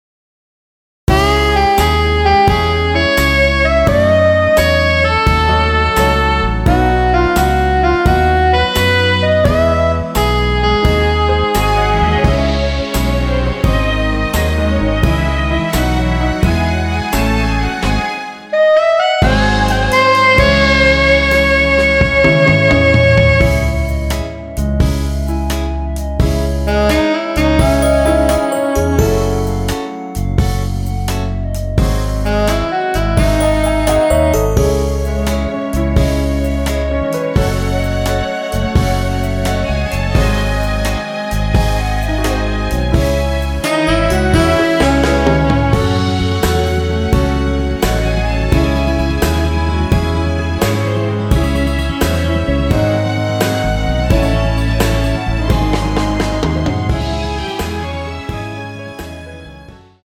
원키에서(-2)내린 멜로디 포함된 MR입니다.(미리듣기 확인)
Dbm
앞부분30초, 뒷부분30초씩 편집해서 올려 드리고 있습니다.
중간에 음이 끈어지고 다시 나오는 이유는